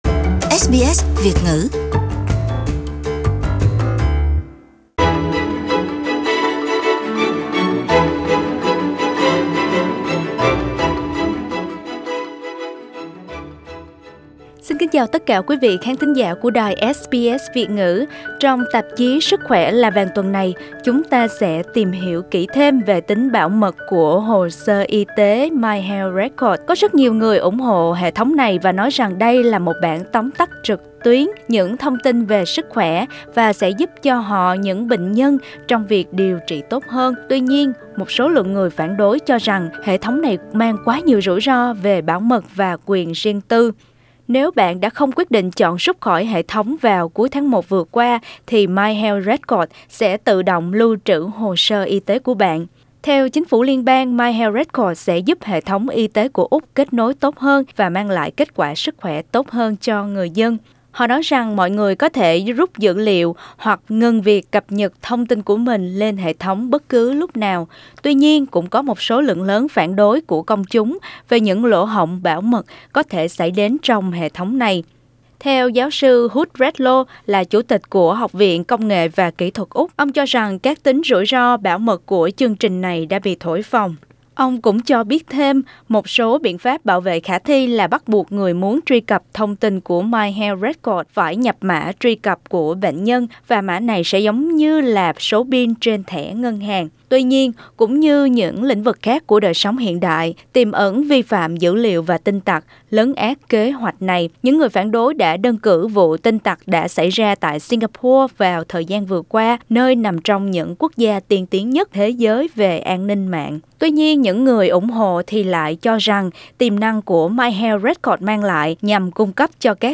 Trong tạp chí Sức khỏe là vàng tuần này, xin mời quý vị thính giả đến với phần trò chuyện với bác sĩ